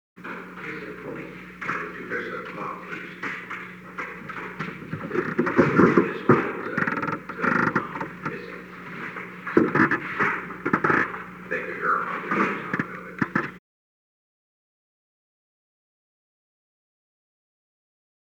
Secret White House Tapes
Conversation No. 919-2
Location: Oval Office
The President met with an unknown man.